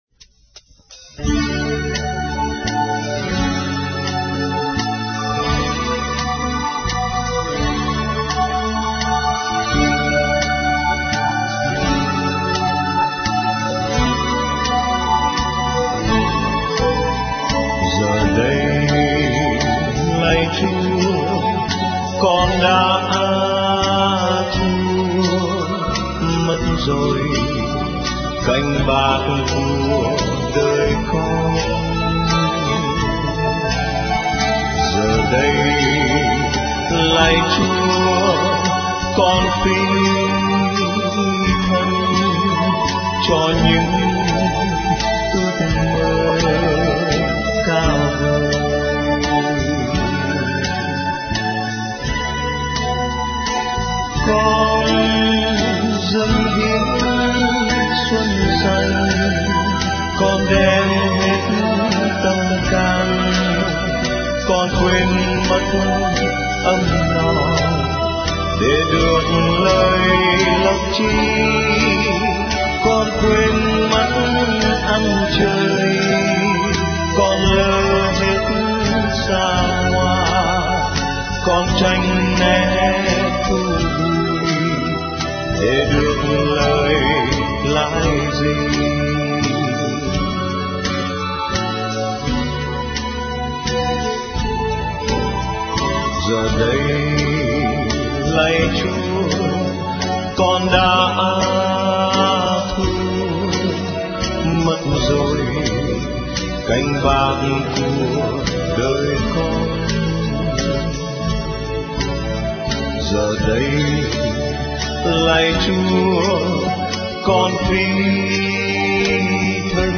* Thể loại: Ngợi ca Thiên Chúa